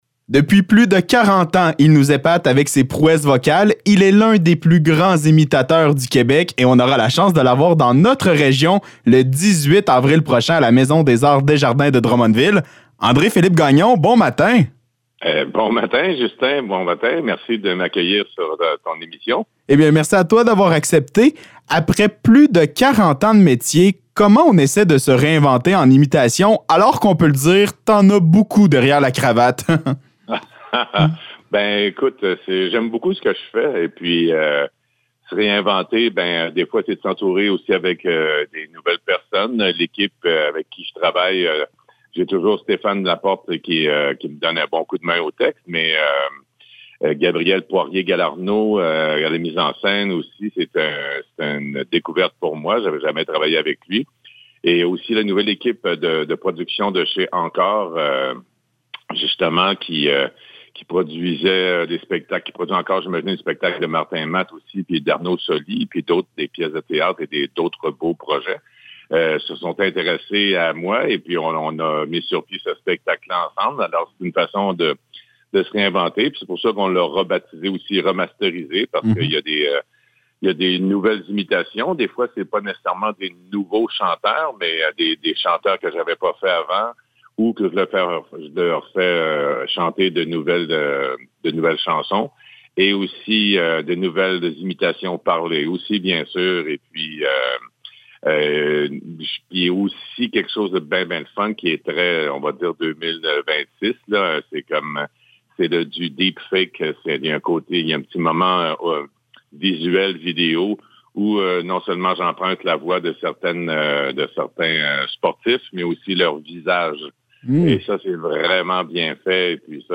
Entrevue avec André-Philippe Gagnon
ENTREVUE-ANDRE-PHILIPPE-GAGNON.mp3